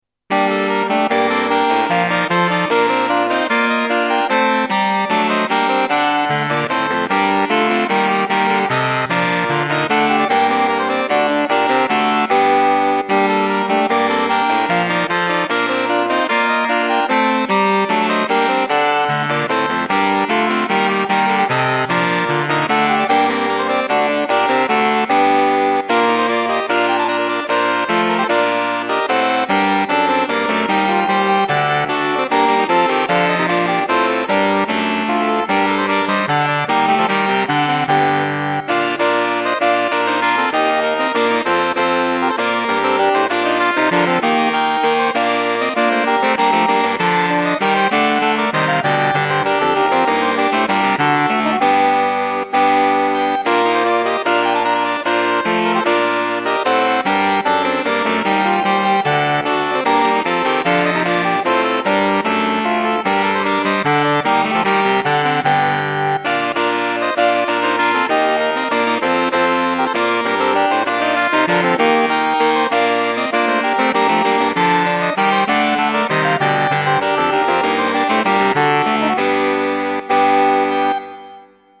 - MES TRANSCRIPTIONS POUR ENSEMBLES DE SAXOPHONES -
SSATB